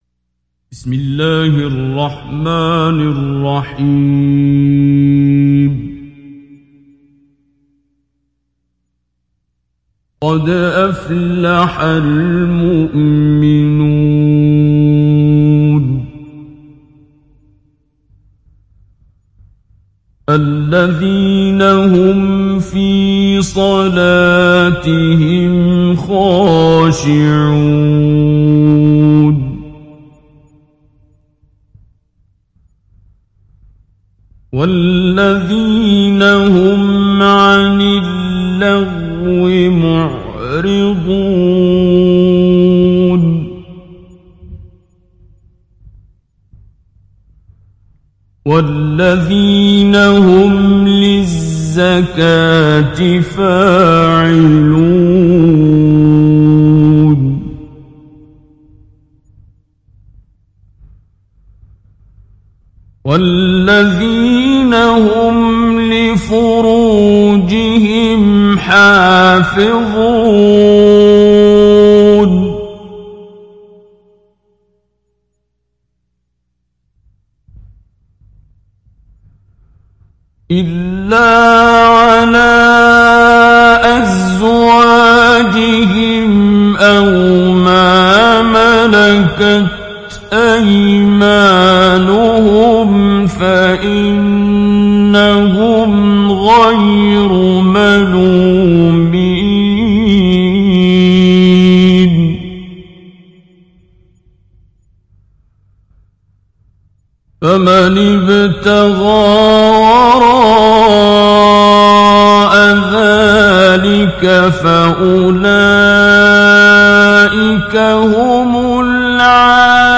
دانلود سوره المؤمنون عبد الباسط عبد الصمد مجود